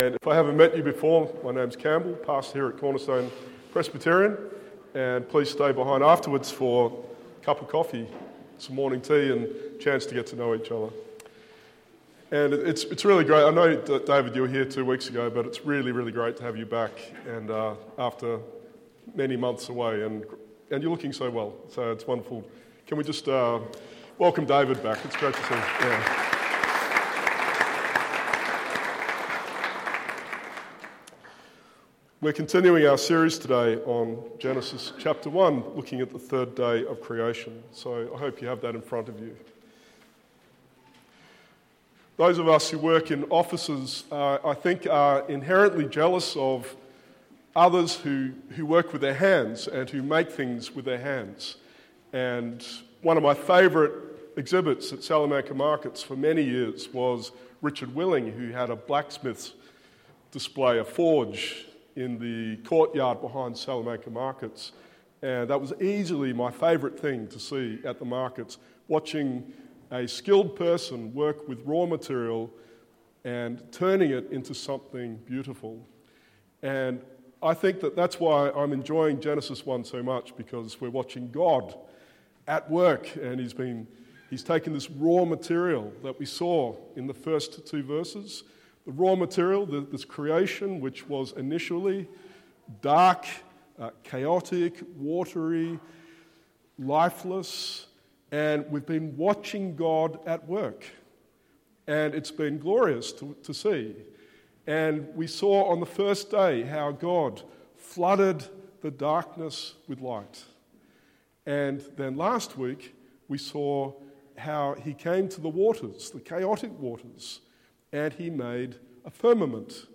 Today at church we examine Day Three of God’s enlightening, separating, enlivening, ordering, and filling of the Heaven and Earth. Watch today as the Living God makes dry land, life, and sustenance for his people!